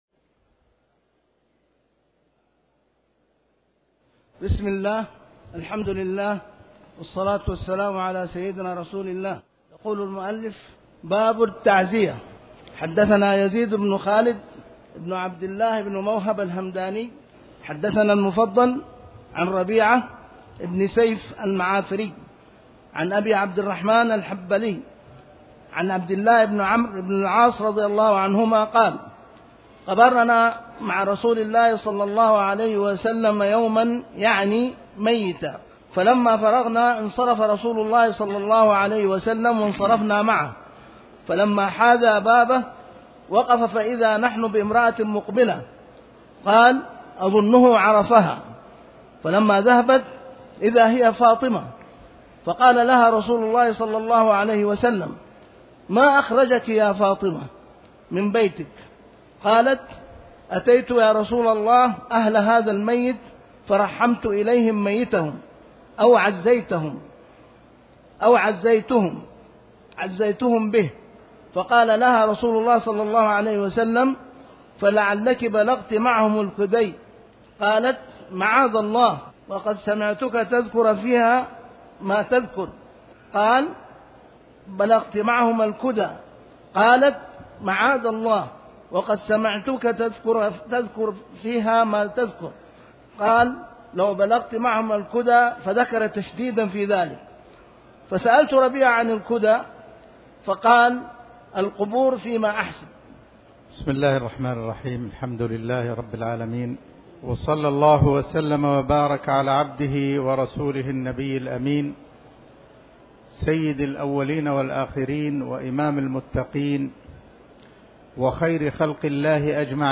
تاريخ النشر ٢٦ محرم ١٤٤٠ هـ المكان: المسجد الحرام الشيخ